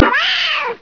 catyell.wav